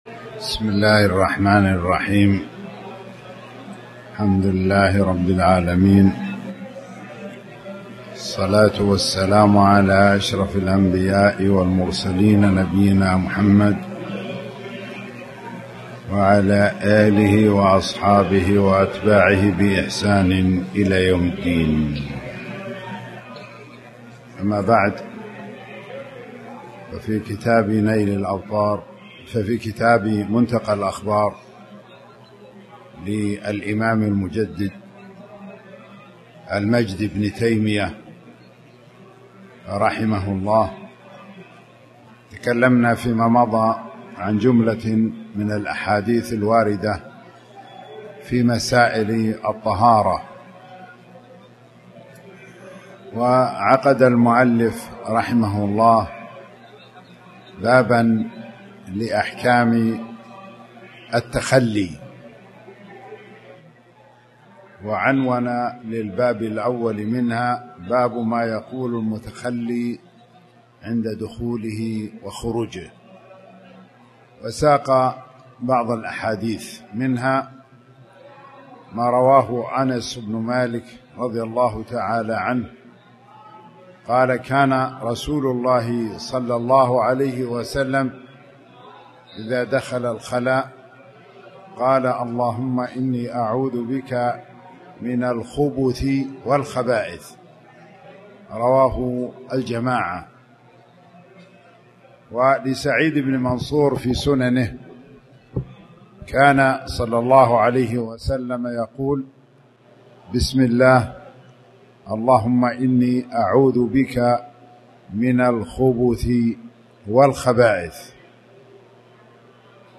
تاريخ النشر ٢٨ ذو الحجة ١٤٣٨ هـ المكان: المسجد الحرام الشيخ